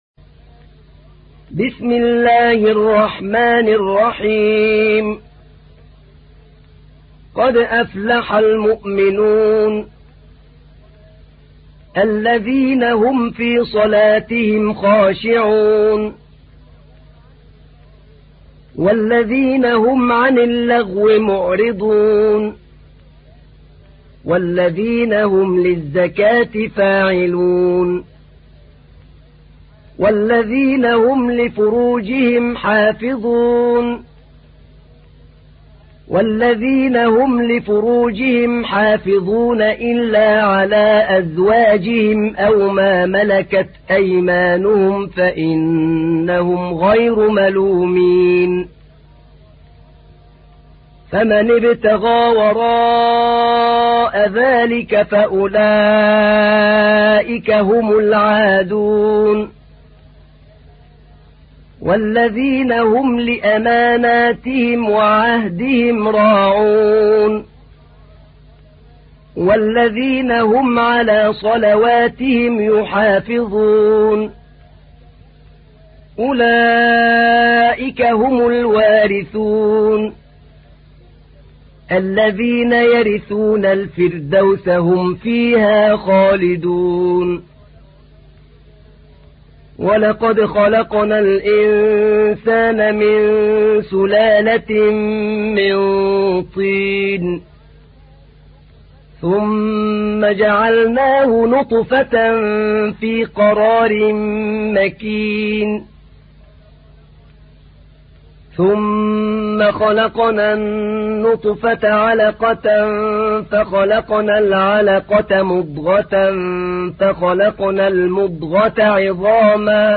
تحميل : 23. سورة المؤمنون / القارئ أحمد نعينع / القرآن الكريم / موقع يا حسين